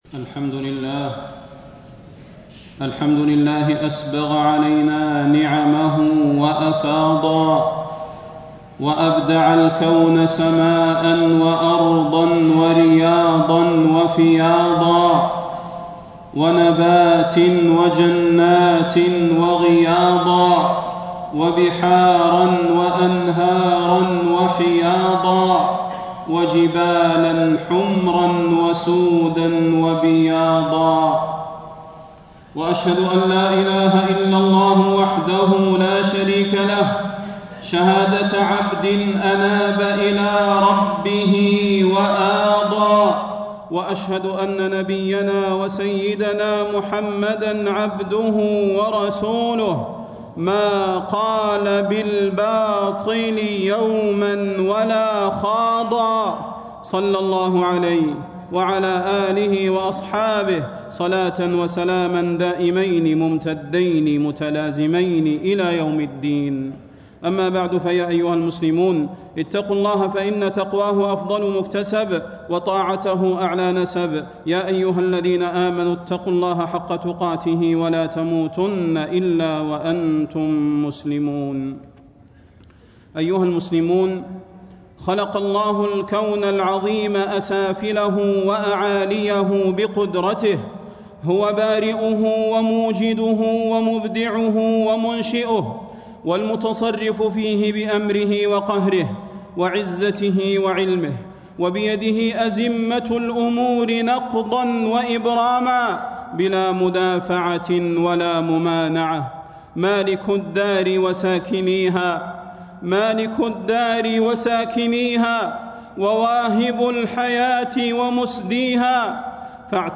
فضيلة الشيخ د. صلاح بن محمد البدير
تاريخ النشر ٢٥ ربيع الأول ١٤٣٦ هـ المكان: المسجد النبوي الشيخ: فضيلة الشيخ د. صلاح بن محمد البدير فضيلة الشيخ د. صلاح بن محمد البدير التفكر في نعم الله ومخلوقاته